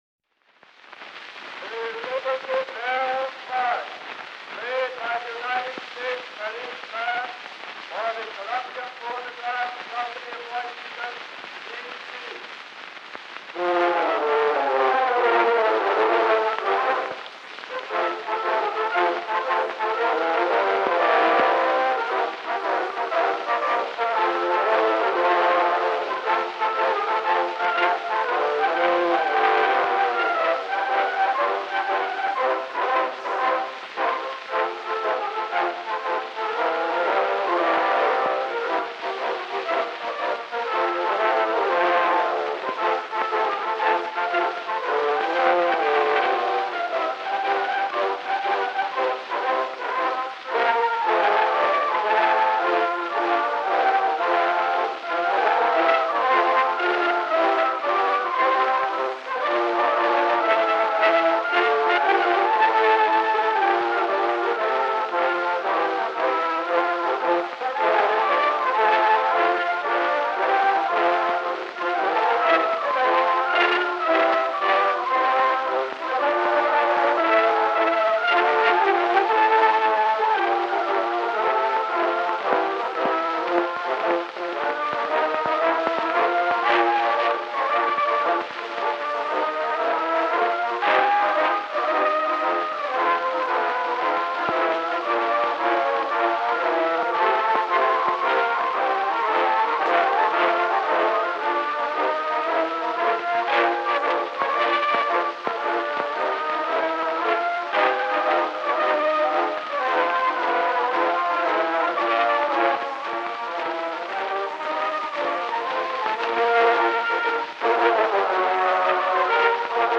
Disc 1: Early Acoustic Recordings
March